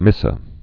(mĭsə)